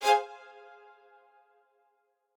strings11_13.ogg